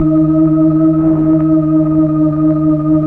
Index of /90_sSampleCDs/Keyboards of The 60's and 70's - CD1/KEY_Optigan/KEY_Optigan Keys